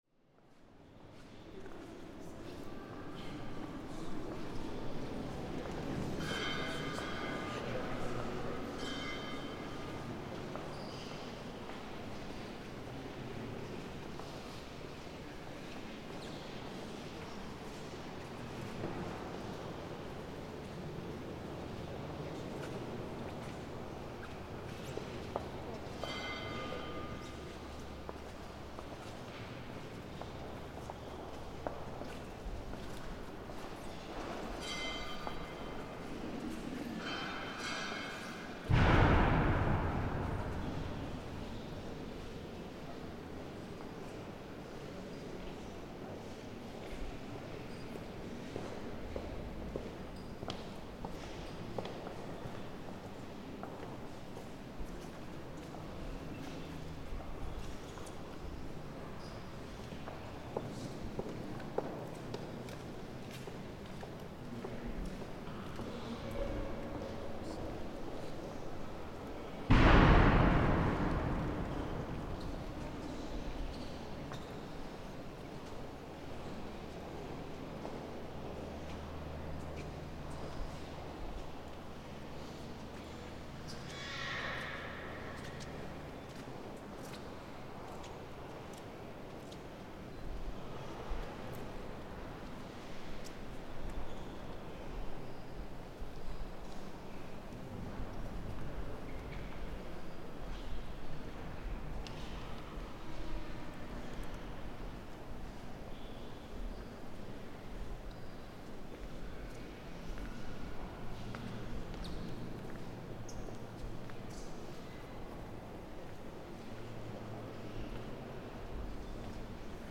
Resonance of Chartres Cathedral
This ambience was recorded just after the annual remembrance service at the UNESCO World Heritage listed Chartres Cathedral.